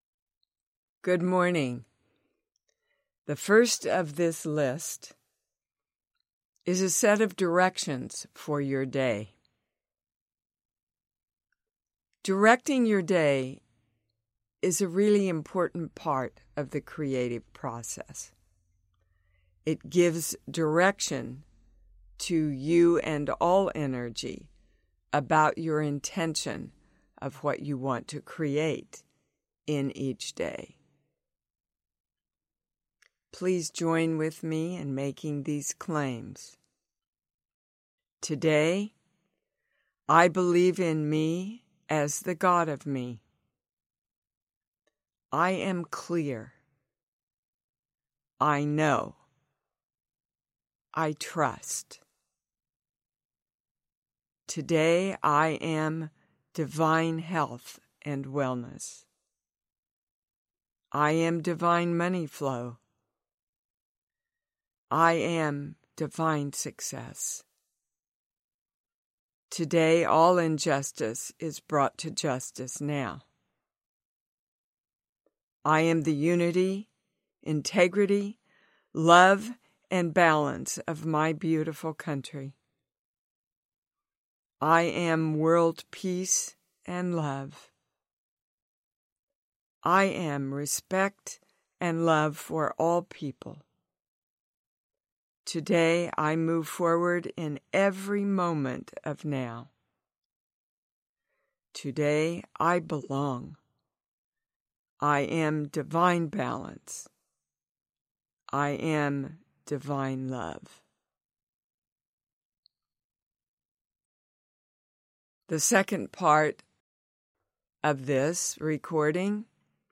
Breathing+Exercise+Meditation.mp3